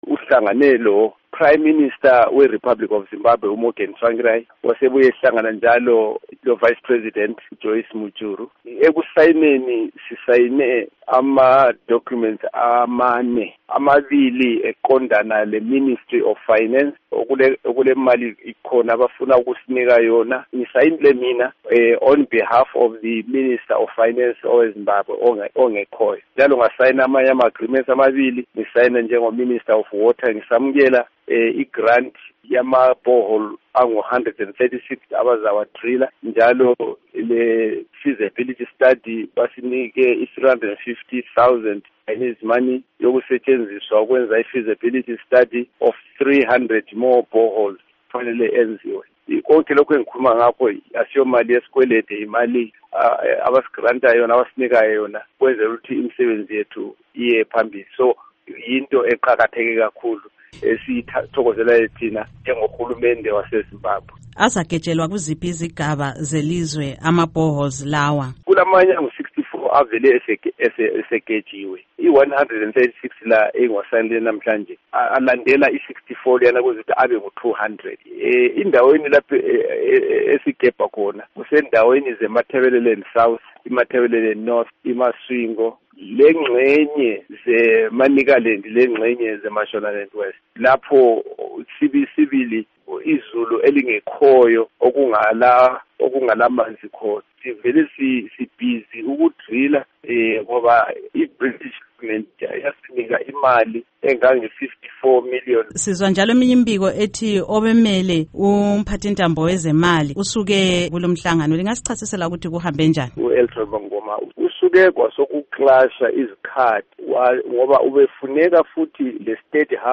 Ingxoxo LoMnu. Samuel Siphepha Nkomo